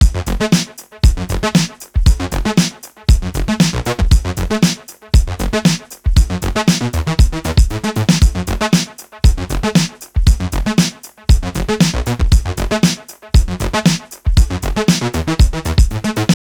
Hi Forum, ich mach´s kurz und bündig, über meine Boxen ist mein Signal druckvoll und hat biss, wenn ich es abspeichere und über die Kopfhörer anhöre, ist...
so, sind nur 3 spuren. hab gestern den transient designer entdeckt, und mich da stunden gespielt am synth. nicht lachen, ich finds geil... zu mindest auf den boxen.